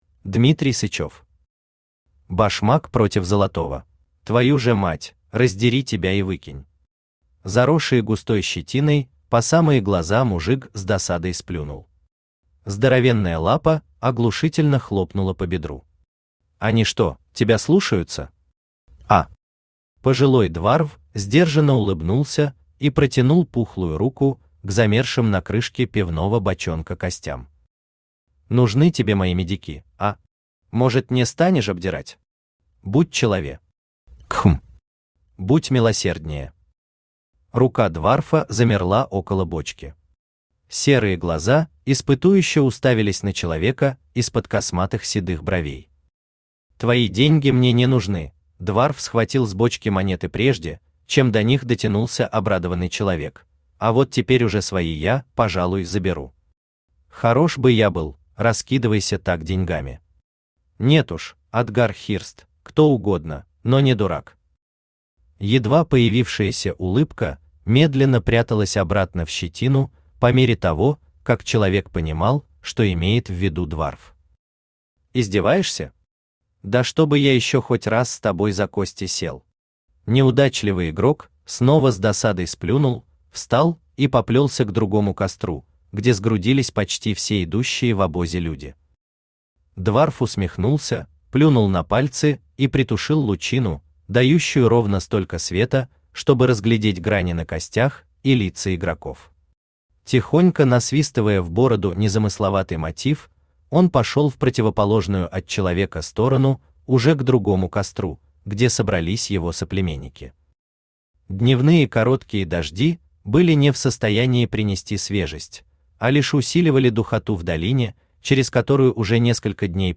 Аудиокнига Башмак против золотого | Библиотека аудиокниг
Aудиокнига Башмак против золотого Автор Дмитрий Юрьевич Сычёв Читает аудиокнигу Авточтец ЛитРес.